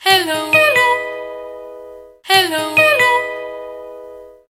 Nada dering the audi s5 sound
Sound Effects